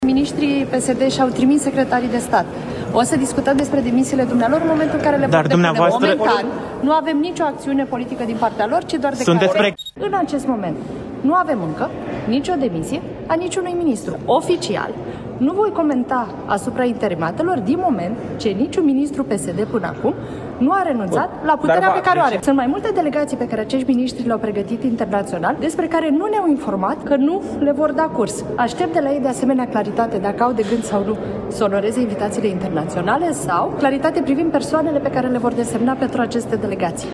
Pe holurile Palatului Victoria, ministra Oana Țoiu a explicat că așteaptă o decizie clară din partea PSD.